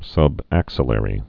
(sŭb-ăksə-lĕrē)